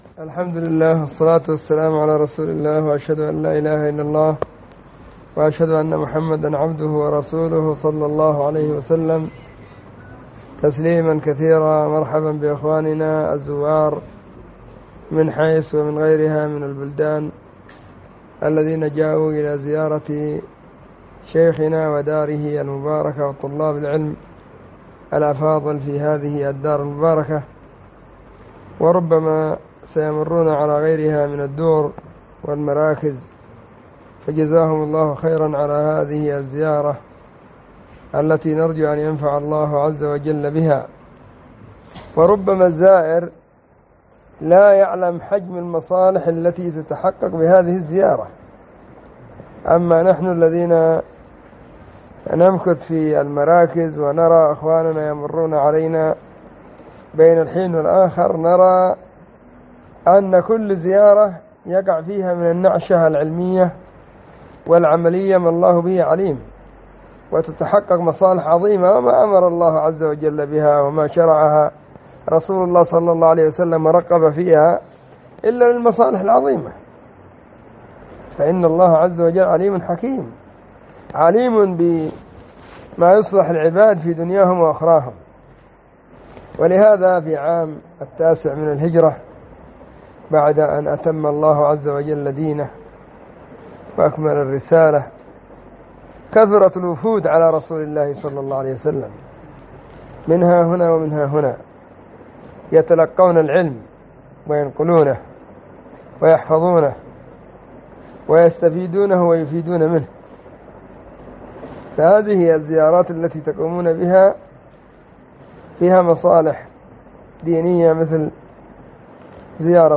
كلمة قيمة بعنوان:كلمة للزوار في مركز العمود رقم 2